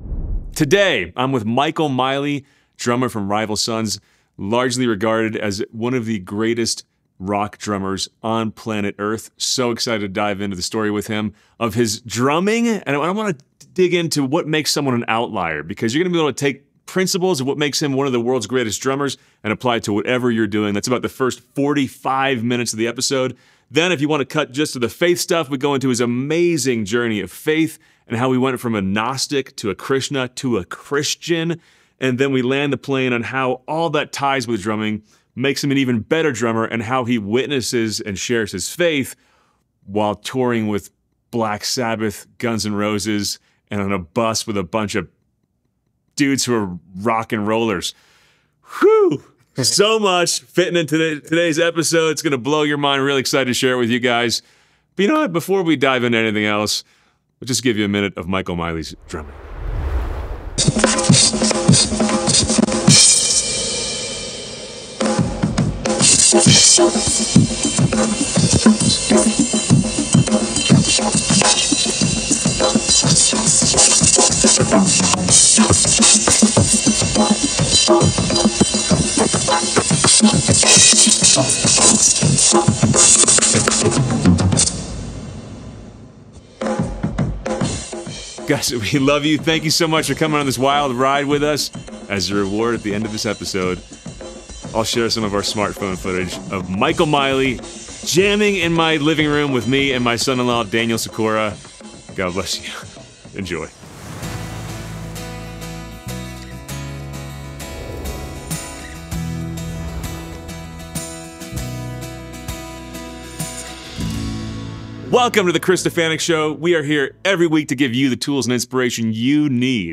In this wide-ranging interview, we discuss secrets to his outlier excellence that can help you in your own life, the wild road to his conversion to Catholicism, and what it's like to witness as a Christian sharing the stage with legendary rockers like Ozzy Osbourne and Guns 'n Roses.